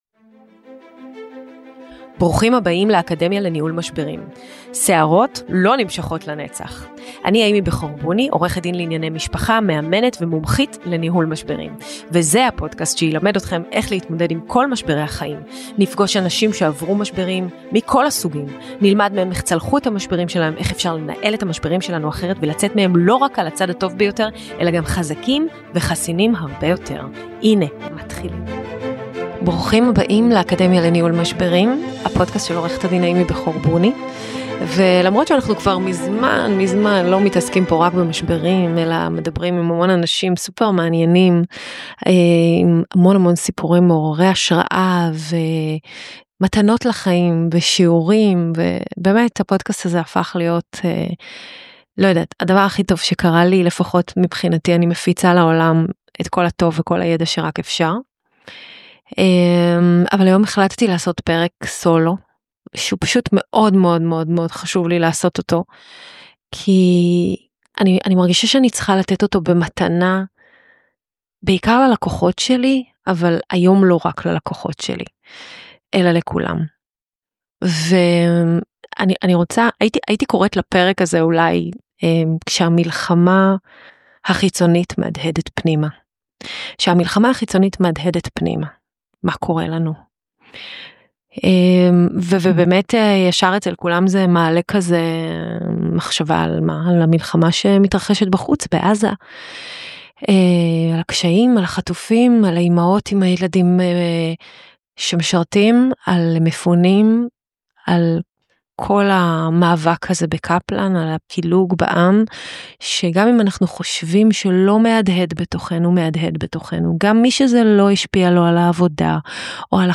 בפרק ה- 129 של הפודקאסט "האקדמיה לניהול משברים", לא אירחתי אף אחד. זהו פרק סולו שמיועד לא רק ללקוחות שלי אלא לכולם.